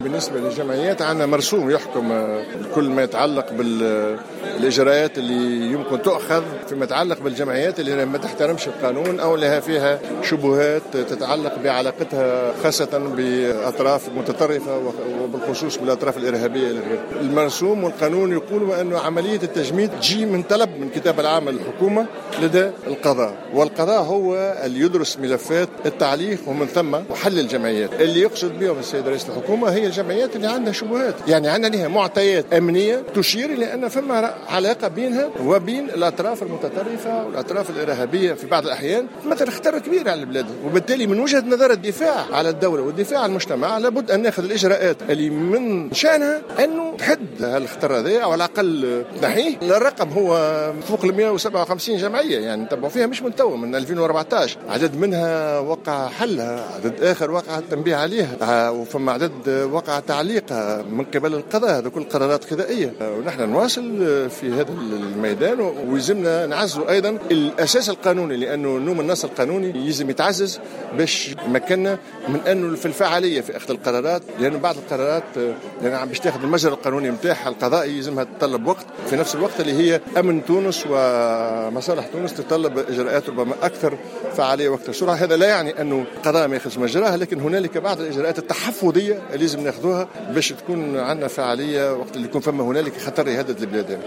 وصرح لمراسلة "الجوهرة أف أم" على هامش اختتام أشغال الملتقى التحضيري بنابل حول الملتقى الوطني للشباب، أن هناك مرسوما حكوميا يتعلق بالجمعيات وأن الجمعيات هي محل متابعة وملاحقة وقد تم تجميد أنشطة بعضها فيما تم حل جمعيات أخرى بقرار قضائي او تعليق نشاطها.